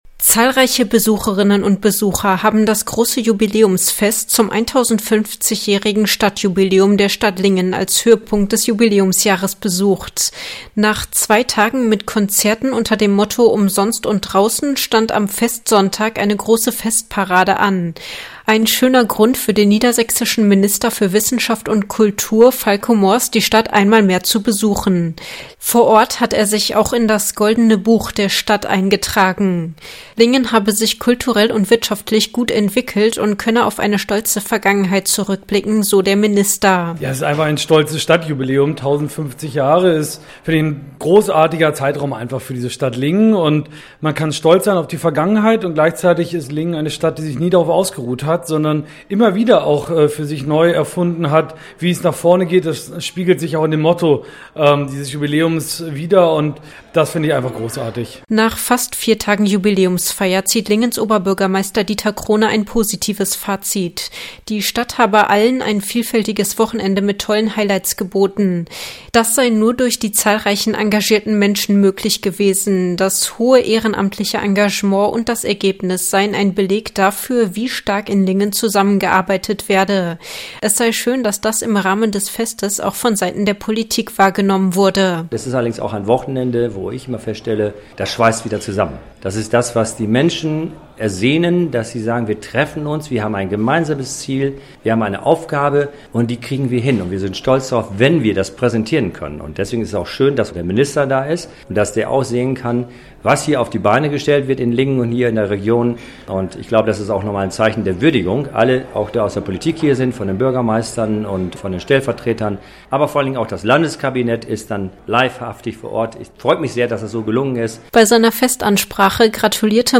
Er war beim großen Jubiläumsfest am vergangenen Wochenende in Lingen und hat sich unter anderem in das Goldene Buch der Stadt eingetragen.